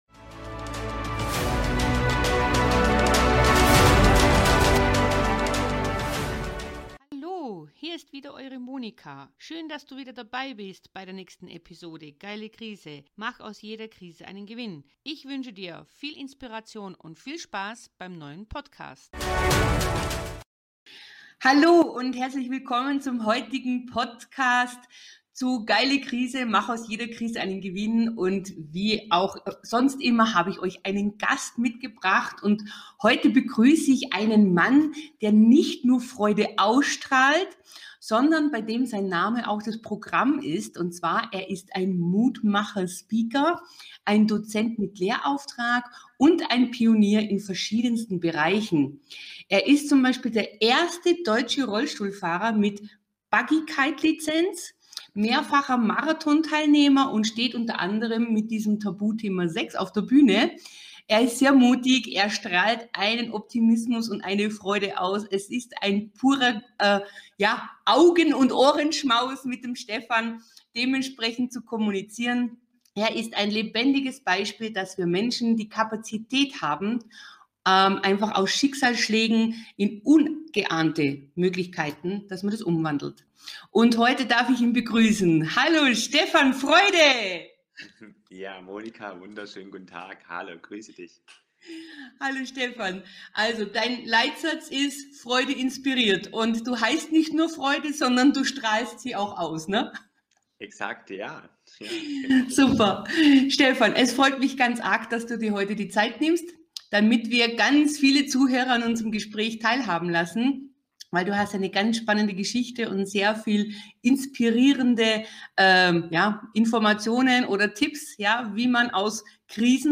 Er sitzt seitdem im Rollstuhl. Er steht auf großen Bühnen, er ist begeisterter Fallschirmspringer, lebt ein glückliches Leben. Wie er das Leben sieht, was er anderen mitgibt, um aus Krisen oder schwierigen Situationen zu kommen, dass erzählt er im Interview... sei gespannt und inspiriert...